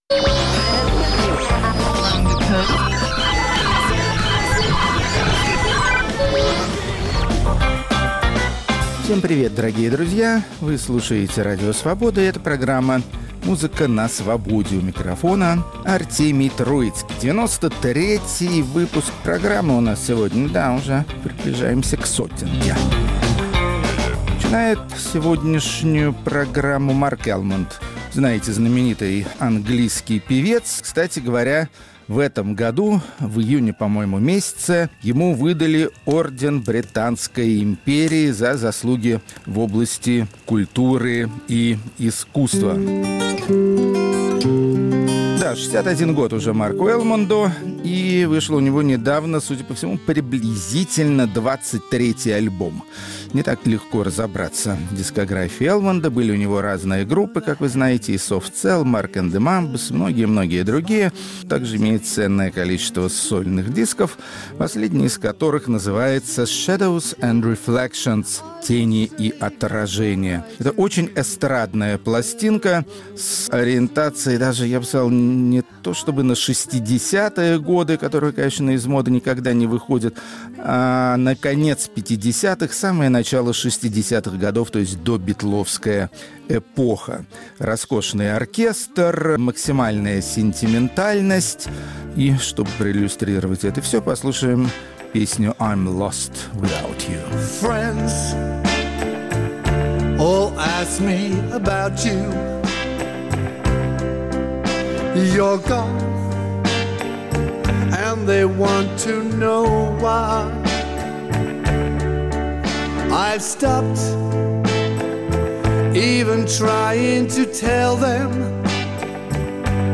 Хедлайнеры девяносто третьего выпуска программы "Музыка на Свободе" – российские исполнители, развивающие традиции международной панковской аудио- и видеокультуры.